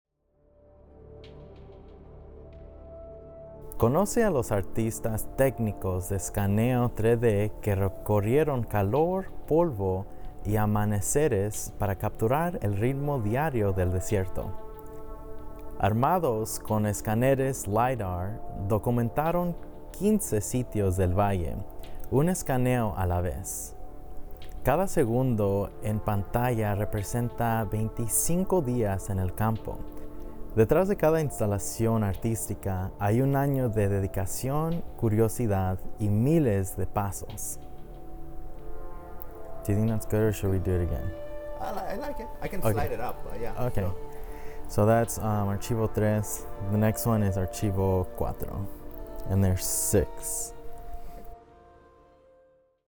Este es un viaje sonoro autoguiado, diseñado para acompañarte mientras te desplazas entre los sitios de las instalaciones.